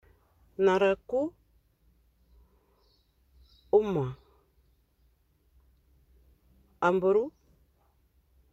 Accueil > Prononciation > a > a